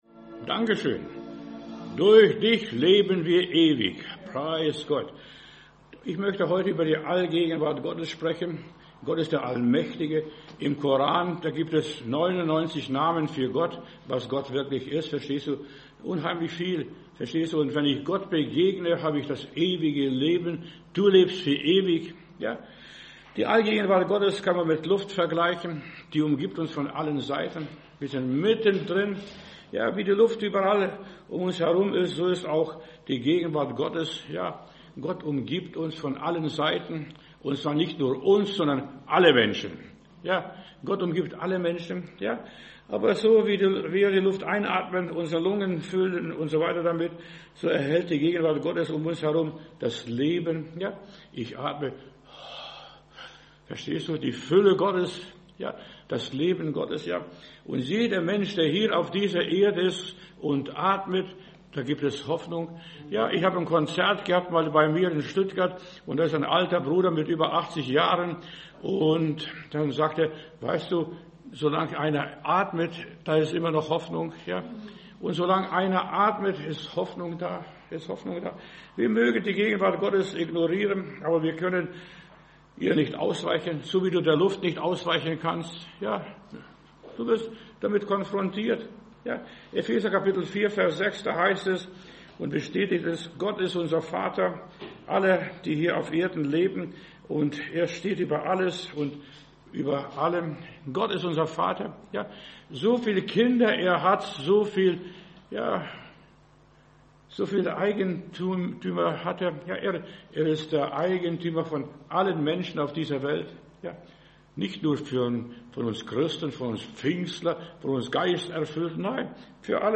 Predigt herunterladen: Audio 2025-09-21 Die Allgegenwart Gottes Video Die Allgegenwart Gottes